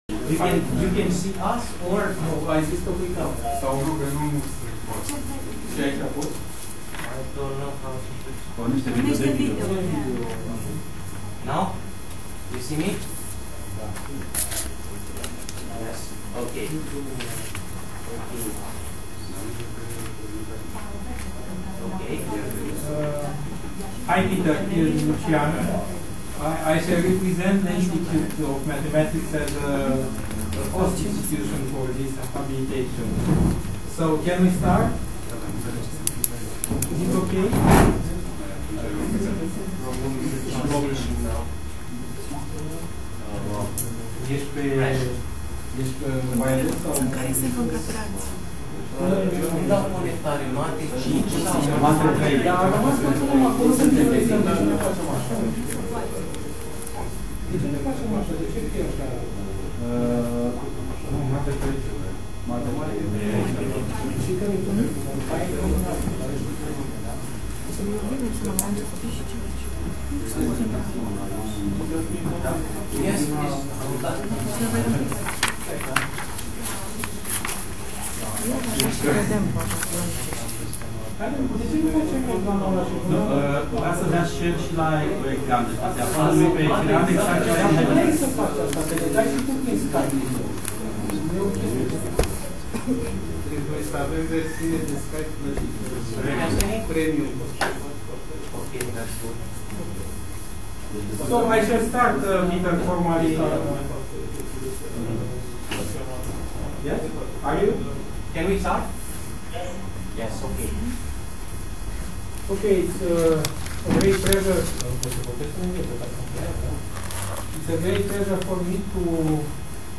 IMAR, Sala 306, Comisia de matematica
inregistrare sustinere teza de abilitare